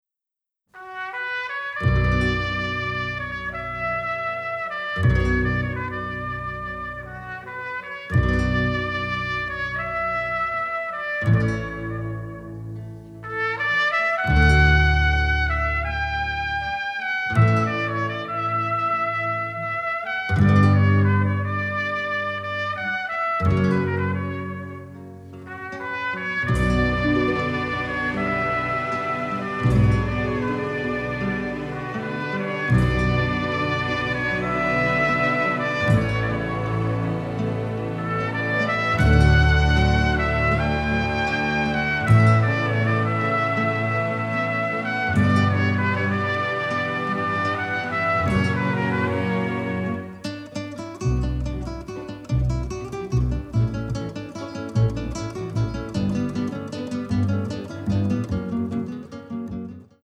catchy, lovely score